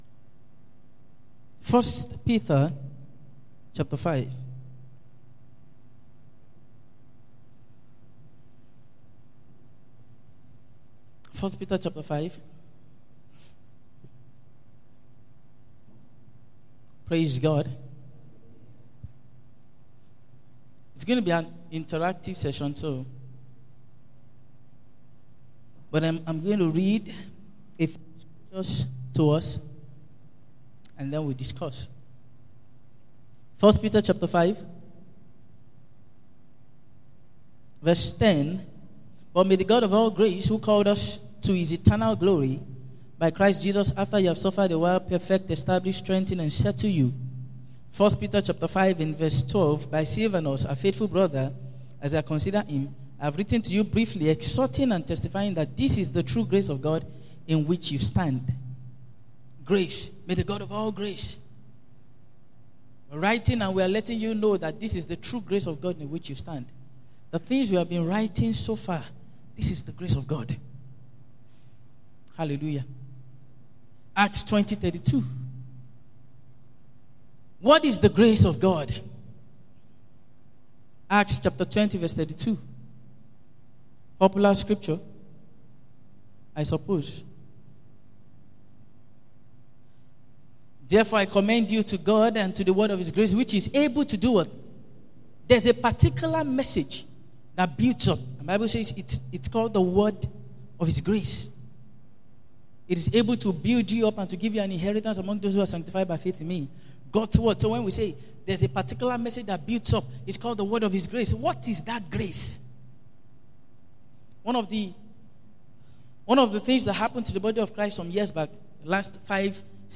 In this teaching, we present a simple, straight to the point explanation of what it means to preach the grace of God.